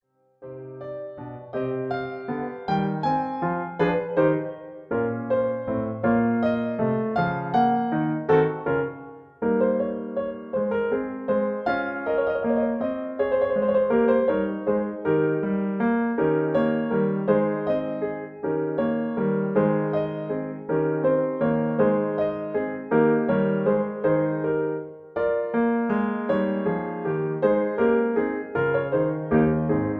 MP3 piano accompaniment
in C minor.